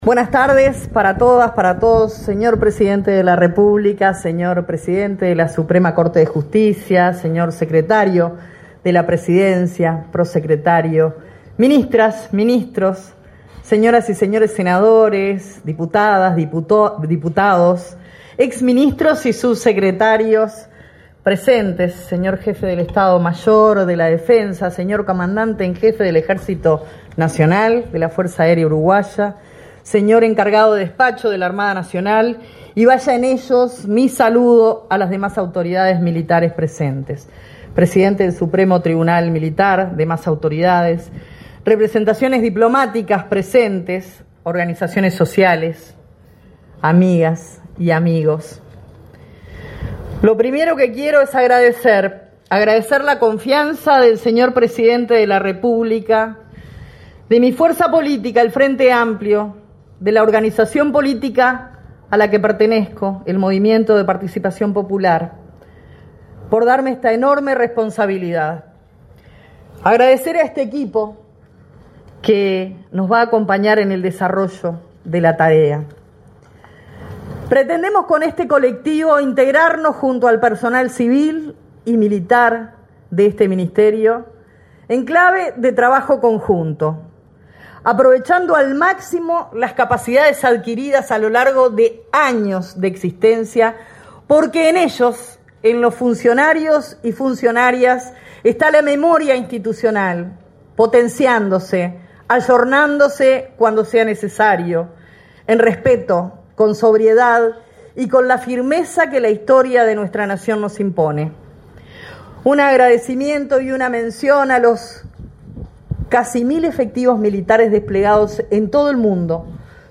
Palabras de la ministra de Defensa Nacional, Sandra Lazo
Palabras de la ministra de Defensa Nacional, Sandra Lazo 07/03/2025 Compartir Facebook X Copiar enlace WhatsApp LinkedIn El presidente de la República, profesor Yamandú Orsi, y la vicepresidenta, Carolina Cosse, asistieron, este 7 de marzo, a la asunción de las autoridades del Ministerio de Defensa Nacional. Sandra Lazo asumió como ministra y Joel Rodríguez como subsecretario.